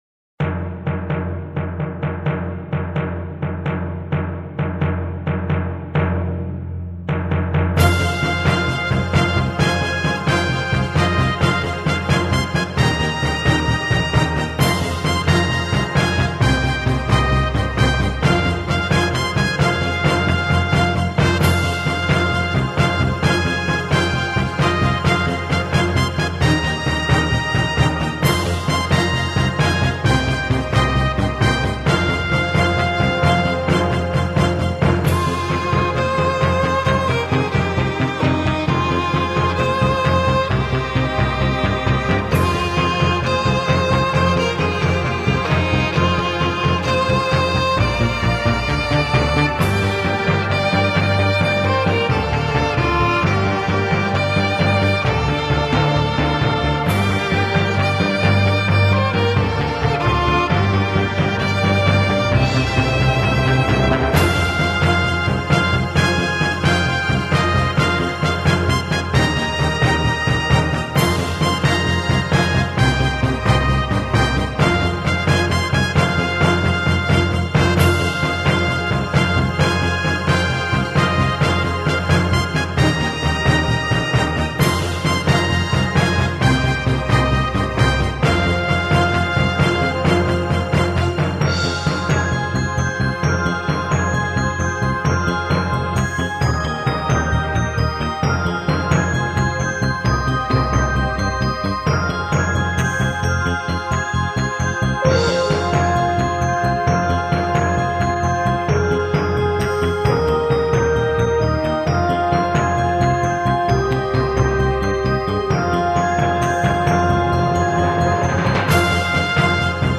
De titelmuziek heet officieel: